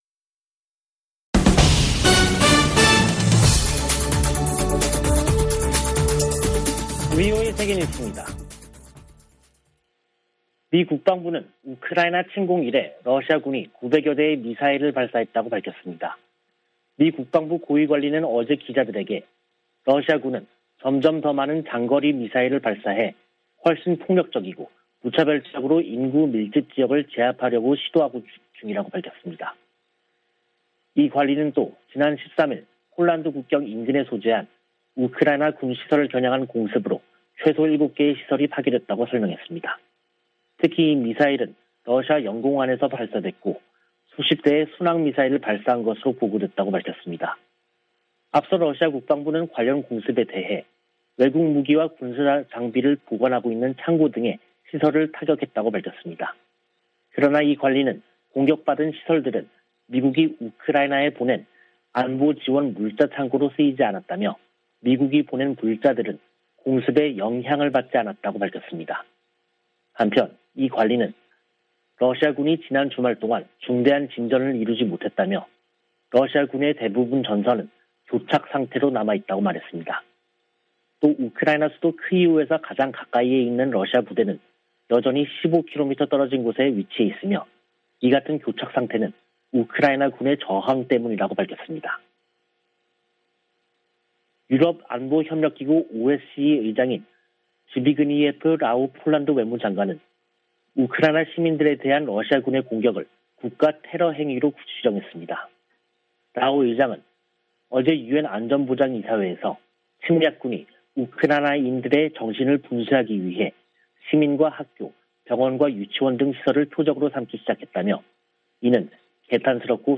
VOA 한국어 간판 뉴스 프로그램 '뉴스 투데이', 2022년 3월 15일 3부 방송입니다. 북한이 이동식발사대(TEL)에서 미사일을 쏠 때 사용하는 콘크리트 토대를 순안공항에 증설한 정황이 포착됐습니다. 백악관은 북한의 신형 ICBM 발사가 임박했다는 보도와 관련해, 예단하지 않겠다고 밝혔습니다. 백악관 국가안보보좌관이 중국 고위 당국자와 만났습니다.